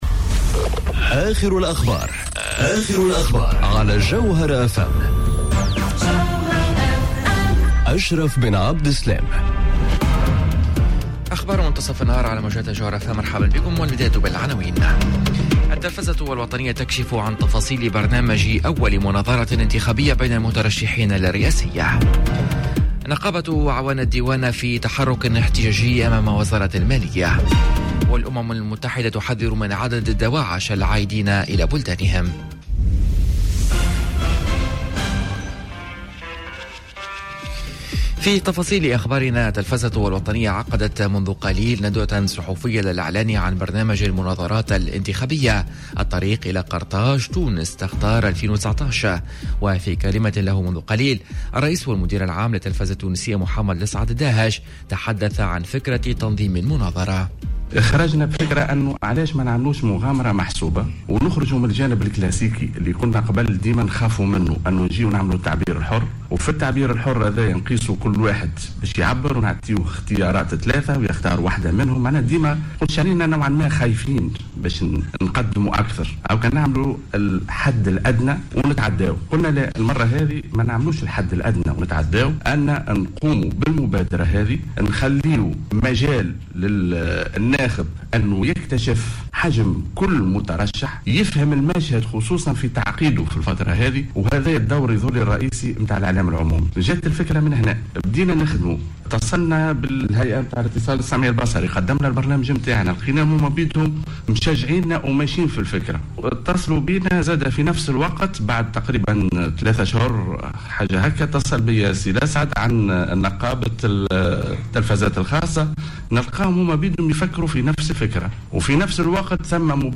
نشرة أخبار منتصف النهار ليوم الإربعاء 28 أوت 2019